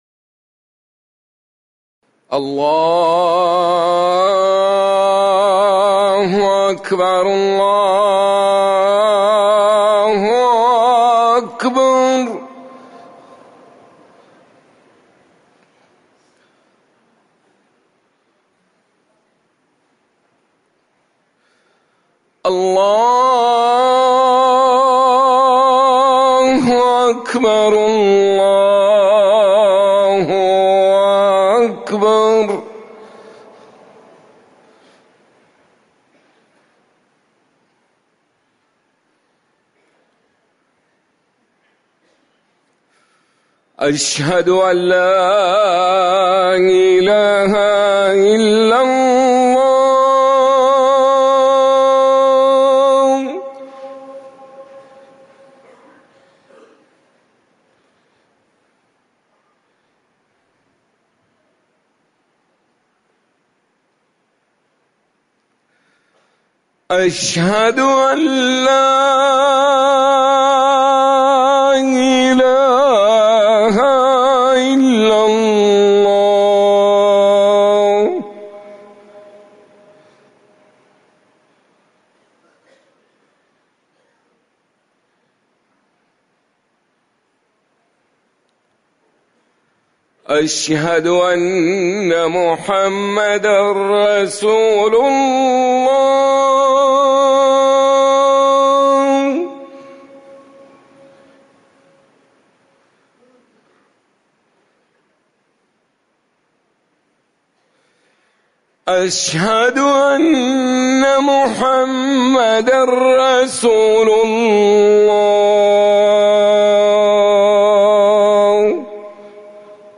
أذان الظهر - الموقع الرسمي لرئاسة الشؤون الدينية بالمسجد النبوي والمسجد الحرام
تاريخ النشر ١٠ صفر ١٤٤١ هـ المكان: المسجد النبوي الشيخ